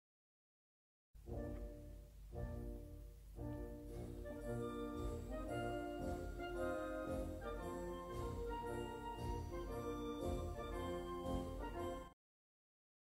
Using words, the rhythm of the new tune goes like this:
ta-taah-ta-taah-ta-taah-ta-taah-ta-taah-ta-taah-ta-taah-ta-taah.